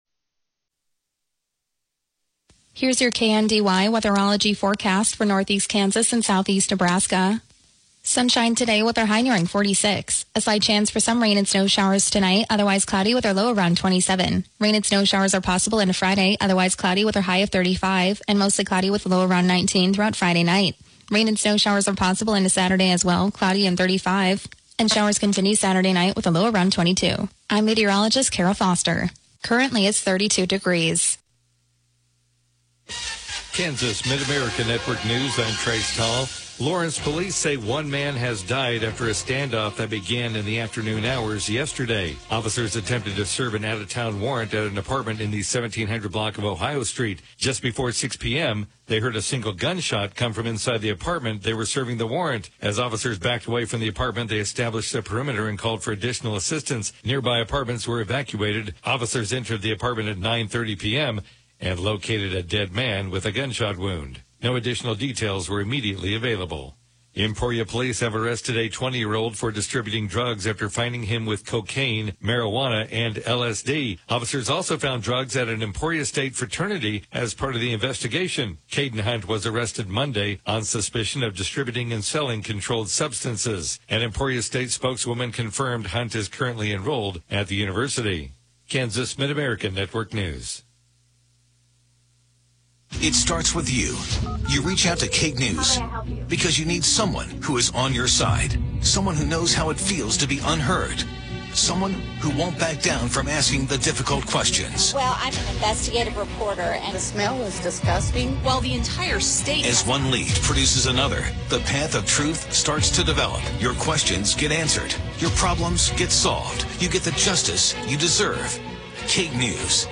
The KNDY Morning News podcast gives you local, regional, and state news as well as relevant information for your farm or home as well. Broadcasts are archived daily as originally broadcast on Classic Country 1570 AM, 94.1 FM KNDY.